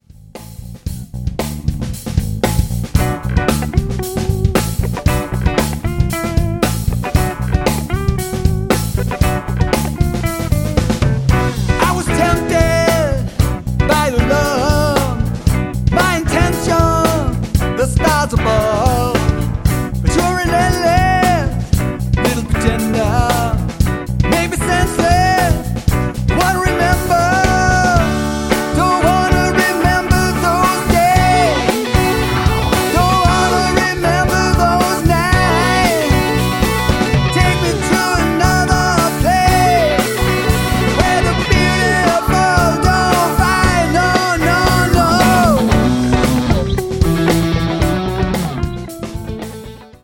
old school rock, funk and R&B
vocals
guitar
keyboards